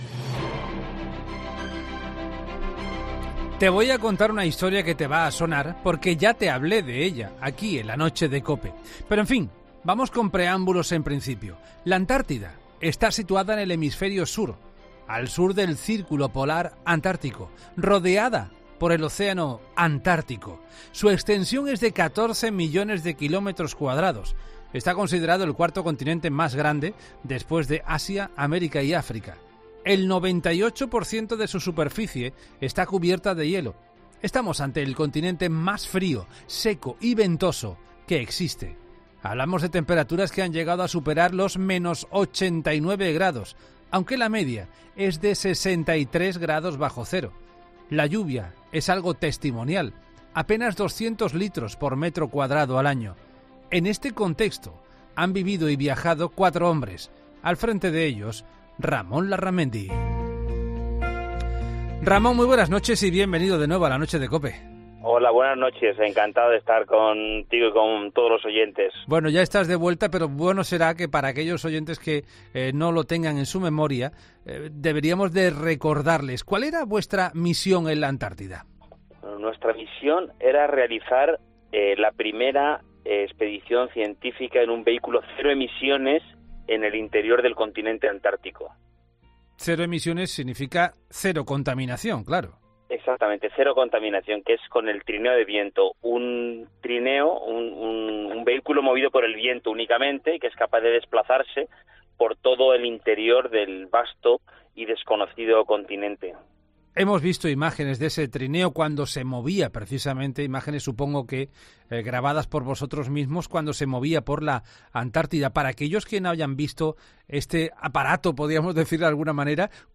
cuenta cómo fue su aventura en 'La Noche de COPE'.